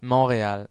^ Canadian English: /ˌmʌntriˈɔːl, mɒn-/ MUN-tree-AWL, MON-;[12][13] French: Montréal, pronounced [mɔ̃ʁeal]
Qc-Montréal.ogg.mp3